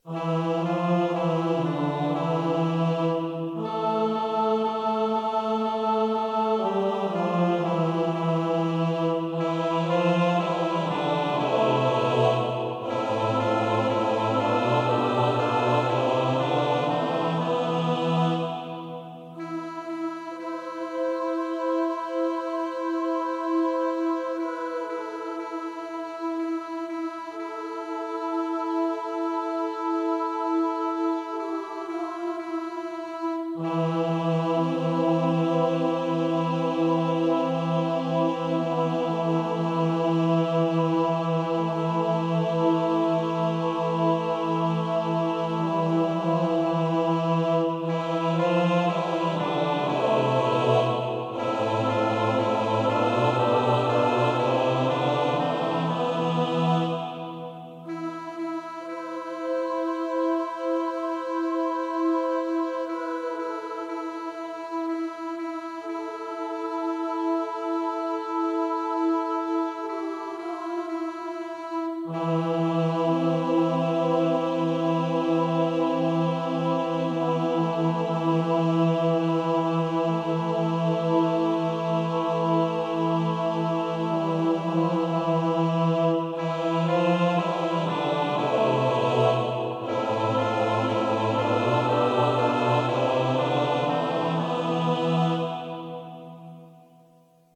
pro smíšený sbor